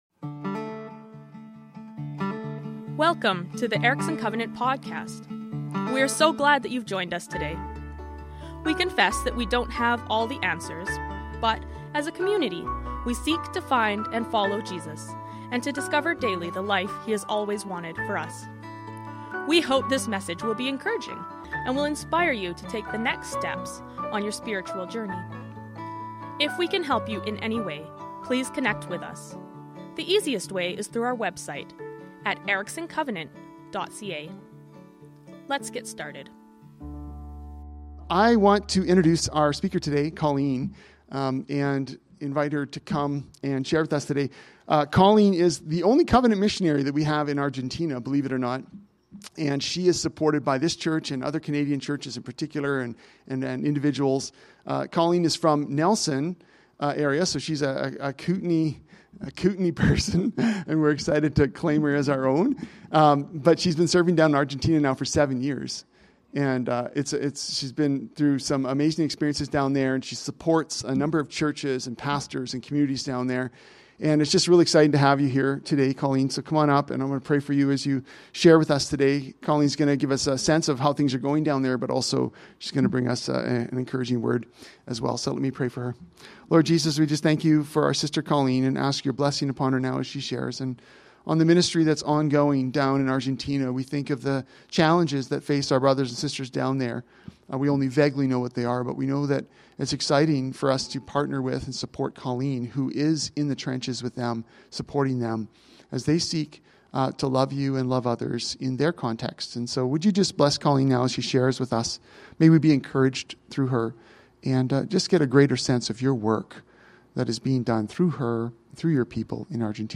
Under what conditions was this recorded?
Location: Erickson Covenant Church